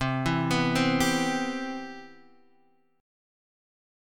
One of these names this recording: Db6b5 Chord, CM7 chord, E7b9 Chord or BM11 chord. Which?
CM7 chord